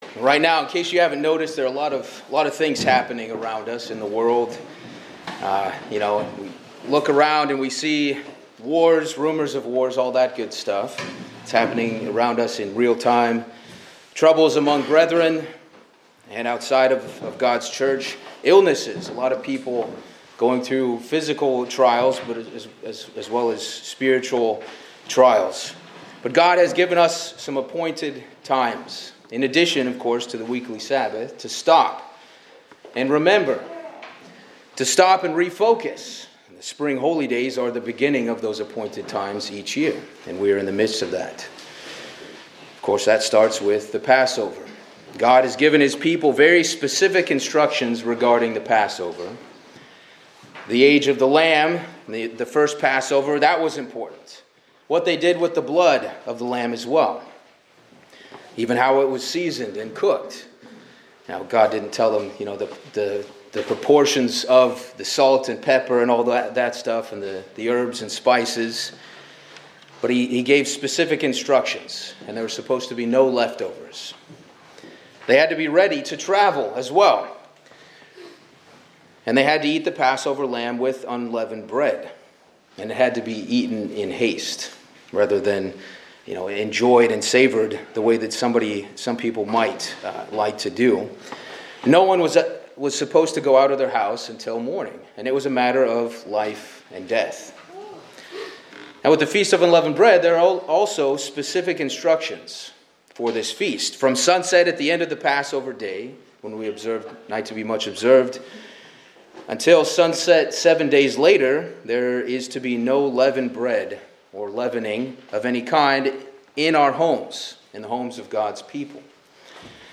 This sermon explores five spiritual lessons drawn from the Feast of Unleavened Bread and the process of de‑leavening. It explains how removing leaven symbolizes the personal effort required to repent of sin, the need for help from God and fellow believers, the reality that sin can hide in unexpected places, and the fact that true cleansing comes from God alone.